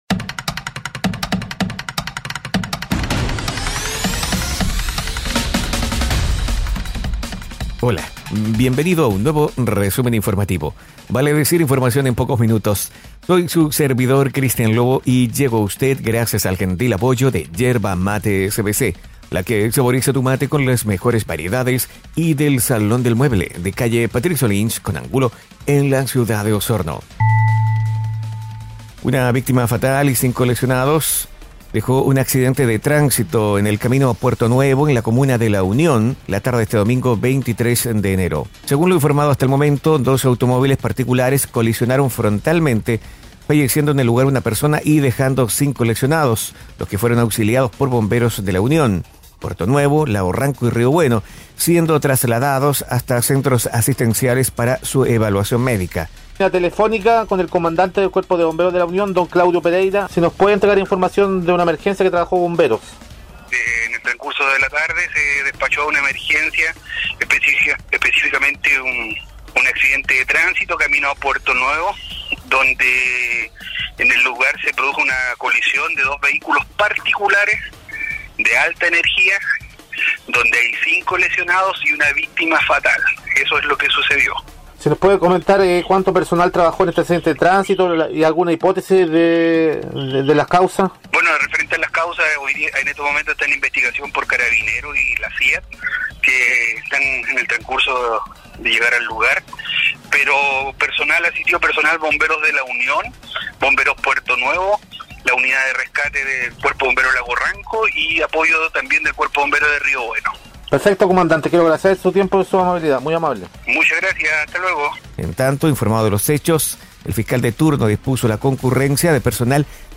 Resumen informativo es un audio podcast con una decena informaciones en pocos minutos